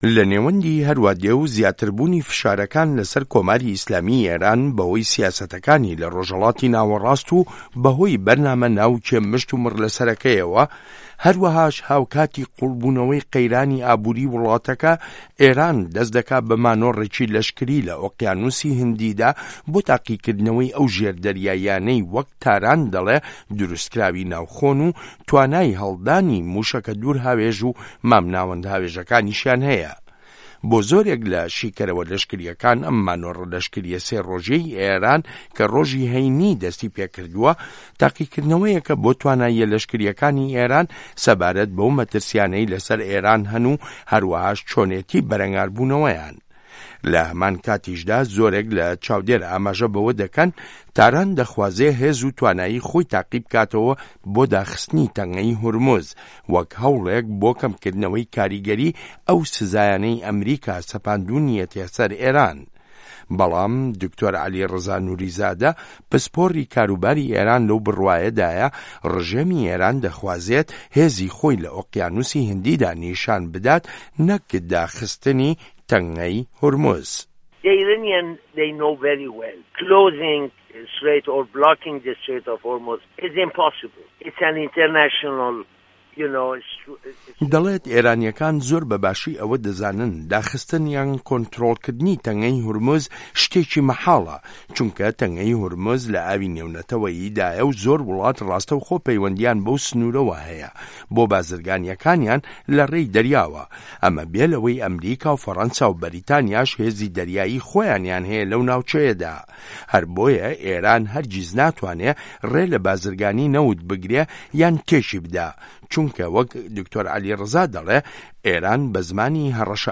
ڕاپۆرتی ئێران و مانۆڕی پیشەسازییە لەشکرییەکانی لە ئۆقیانوسی هیندی